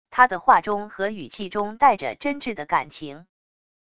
他的话中和语气中带着真挚的感情wav音频生成系统WAV Audio Player